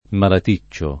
malaticcio